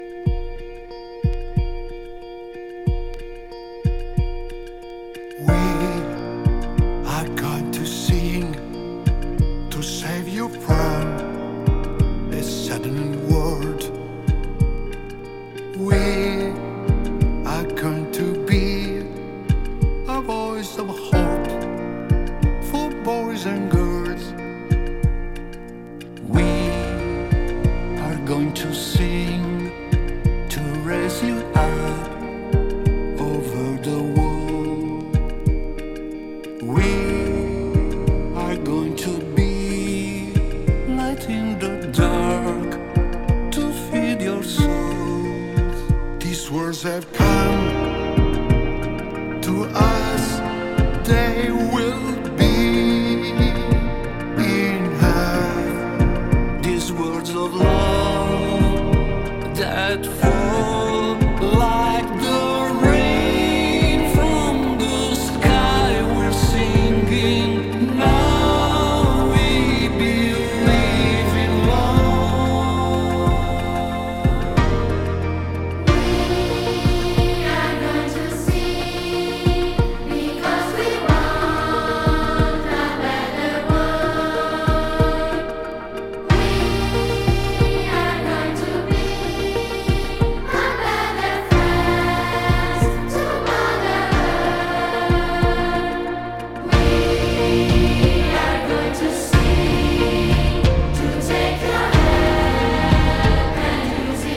程よいヘッポコ感がナイス。